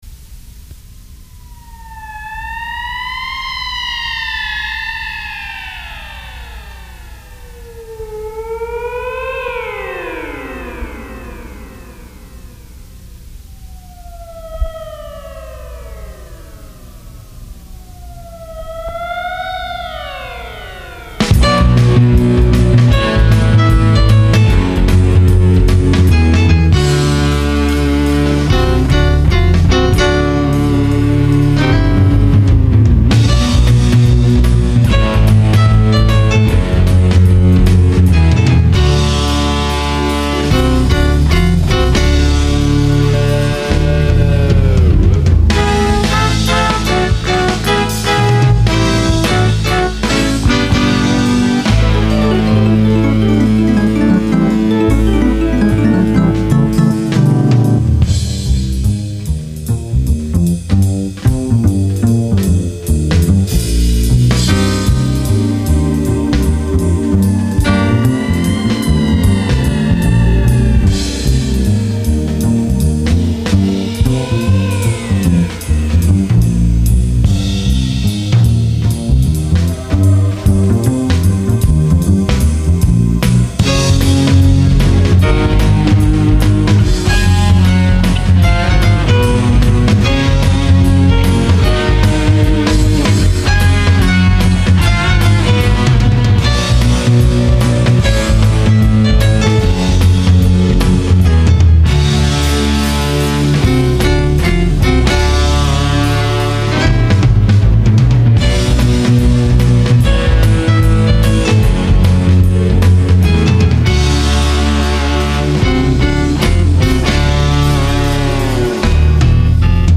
Carvin guitar, Ensonique Mirage and Korg M1 keyboards, bass
Drums